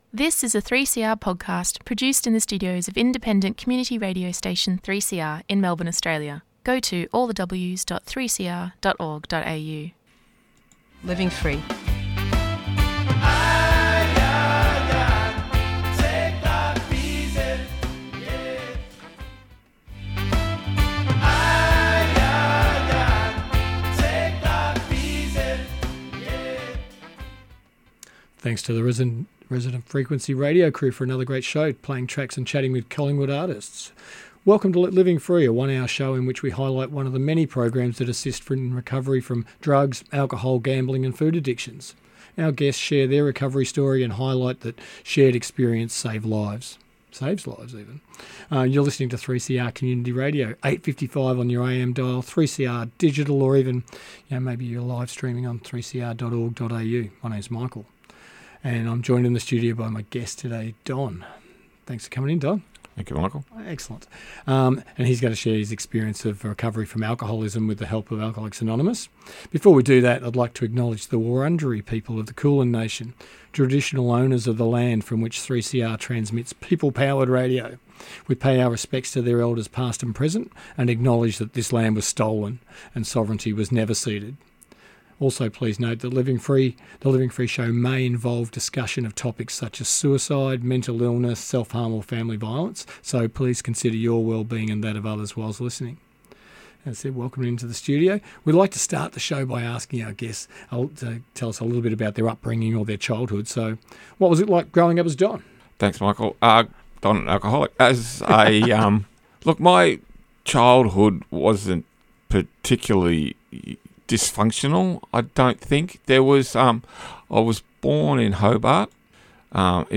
Tweet Living Free Thursday 1:00pm to 2:00pm Talk on substance misuse, alcoholism and other addictions.